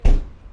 遗骸 " 床垫上的木头 002
描述：木头落在床垫上。户外。
Tag: 下降 秋天 货架 下探